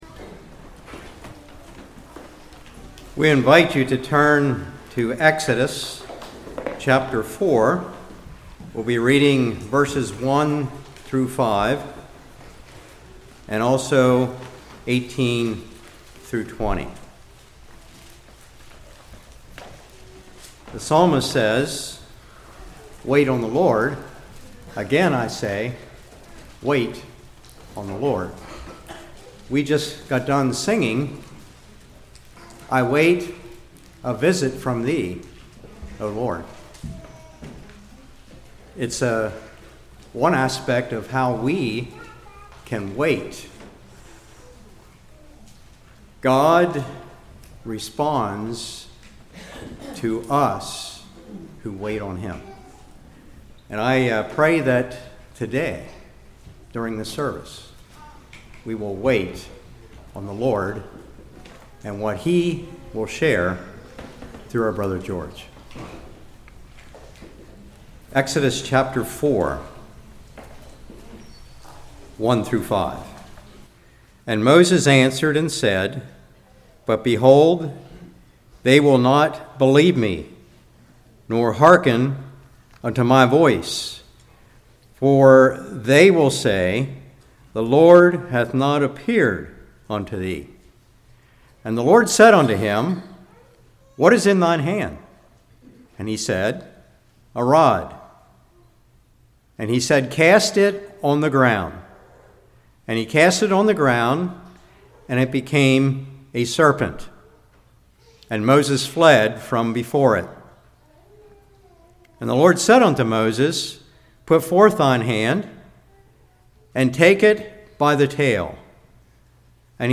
18-20 Service Type: Morning The Rod of Moses